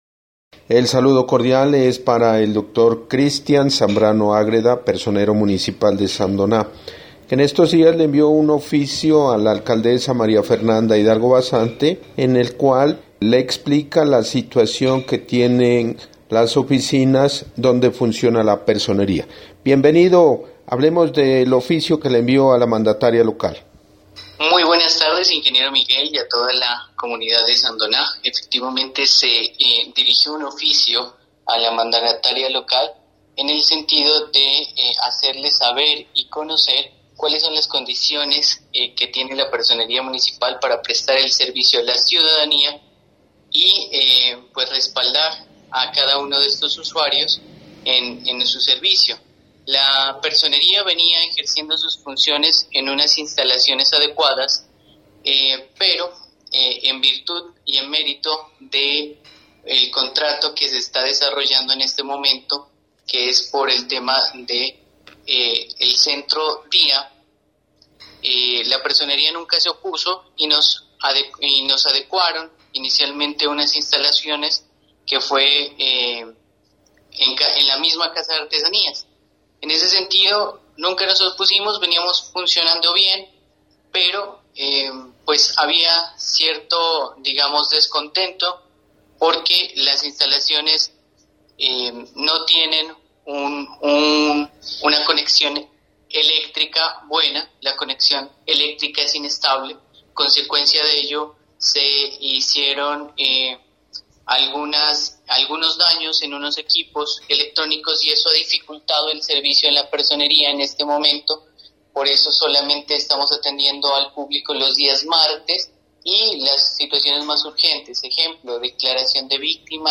Entrevista con el personero de Sandoná: